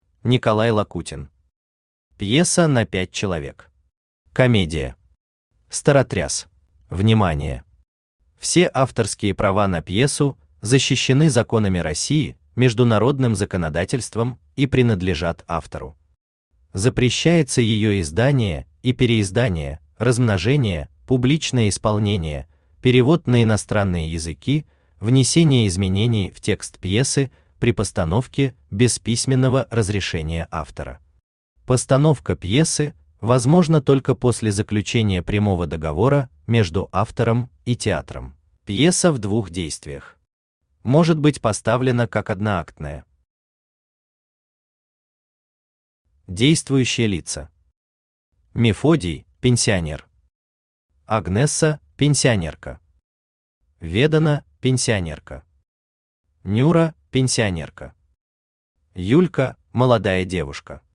Аудиокнига Пьеса на 5 человек. Комедия. Старотряс | Библиотека аудиокниг
Старотряс Автор Николай Владимирович Лакутин Читает аудиокнигу Авточтец ЛитРес.